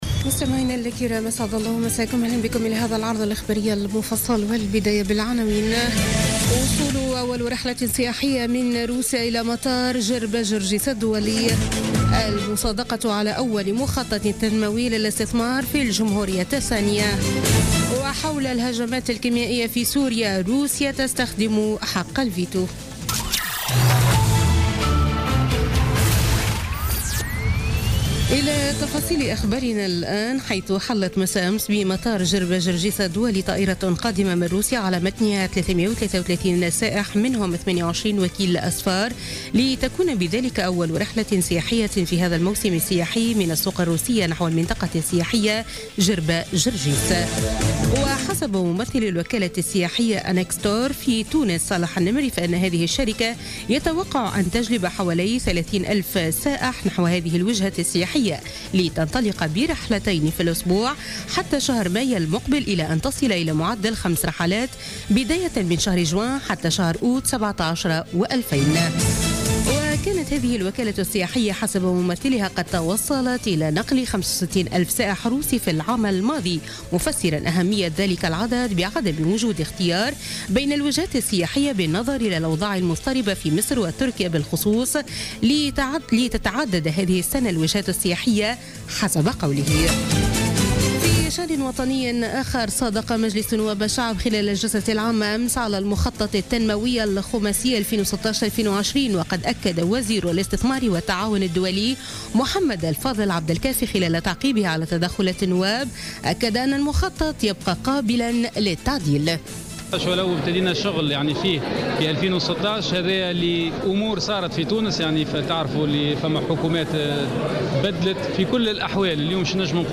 نشرة أخبار منتصف الليل ليوم الخميس 13 أفريل 2017